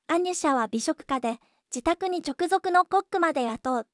voicevox-voice-corpus